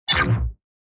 Stealth, Spy, Game Menu, Ui Error Sound Effect Download | Gfx Sounds
Stealth-spy-game-menu-ui-error.mp3